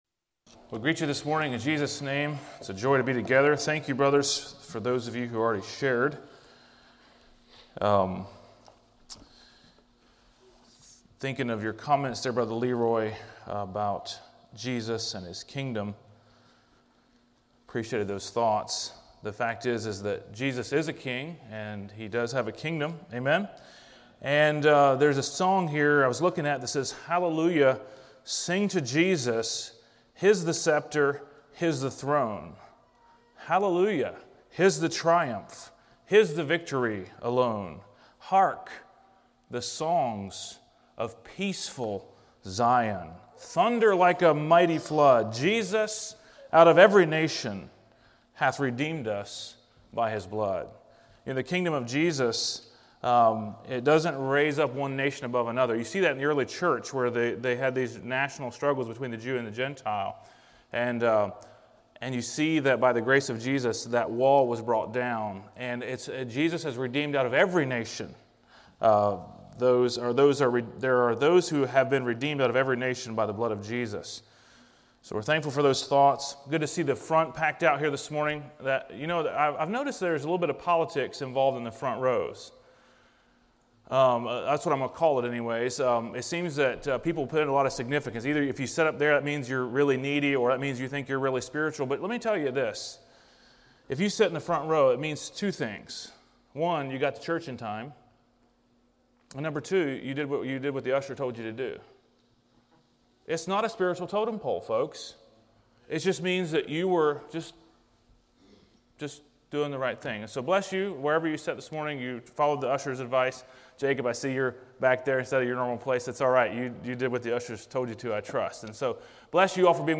Sermons of 2022 - Blessed Hope Christian Fellowship